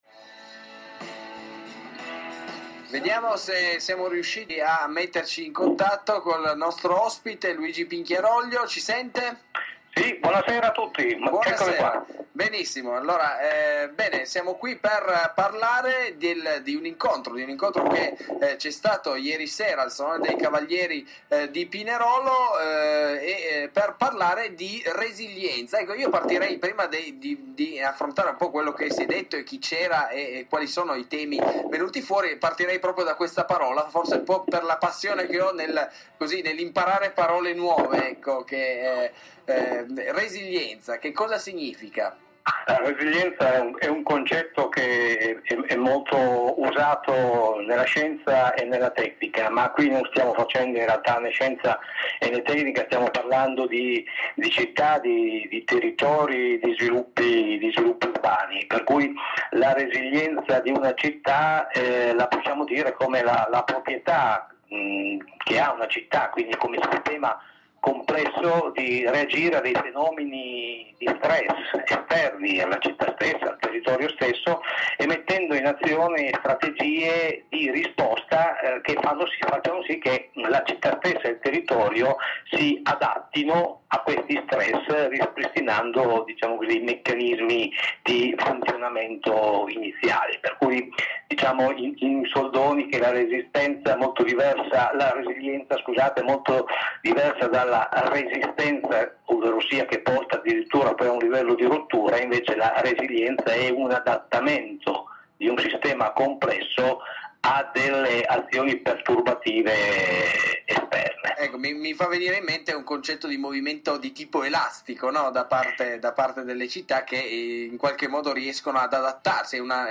Intervista telefonica di Radio Beckwith